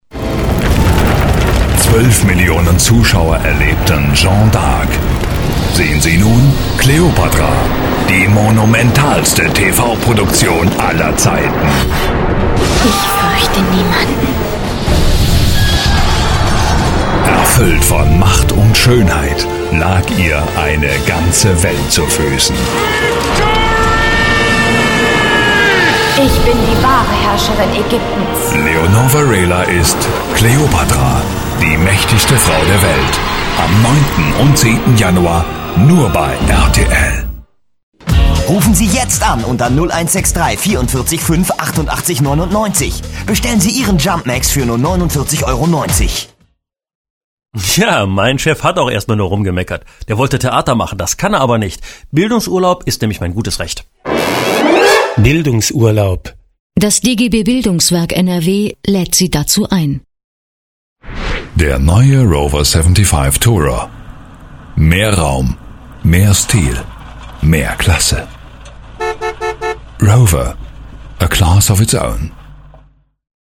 Hörbeispiele WERBUNG / TRAILER Hörbeispiele WERBUNG / TRAILER